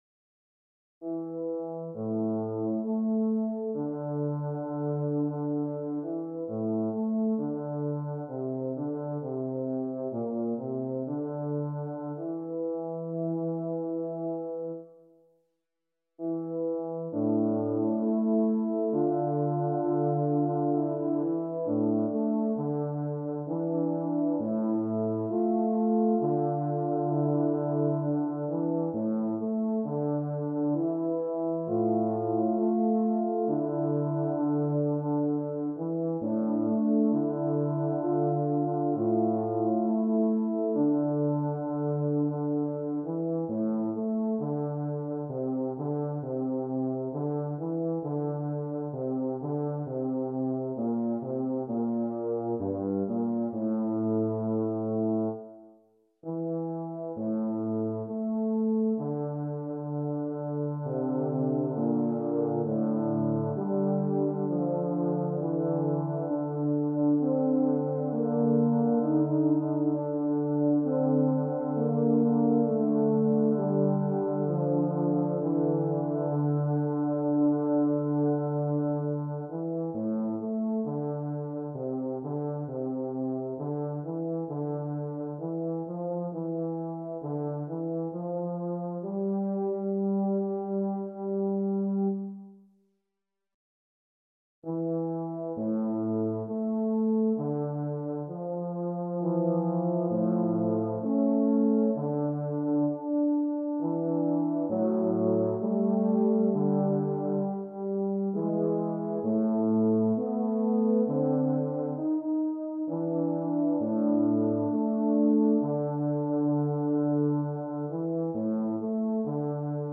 Voicing: Euphonium Duet